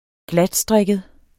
Udtale [ -ˌsdʁεgəð ]